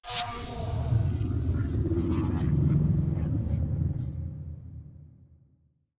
Horror11.ogg